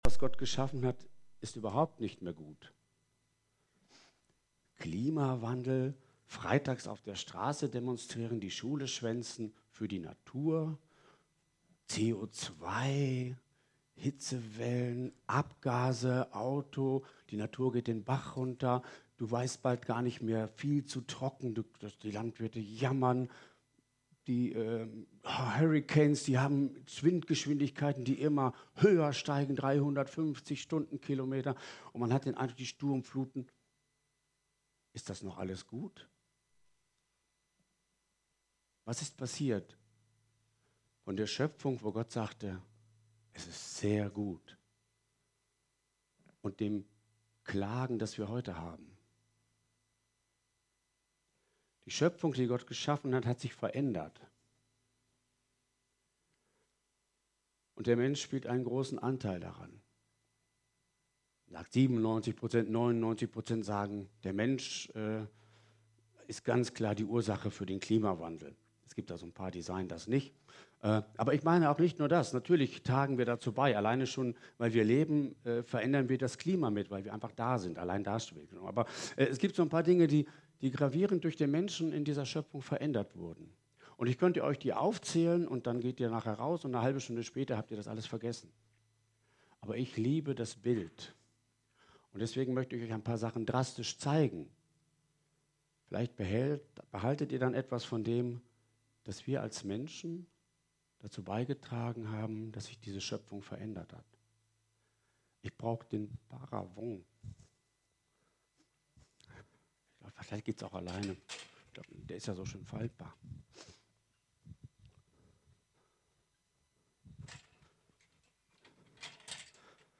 Predigt vom 3.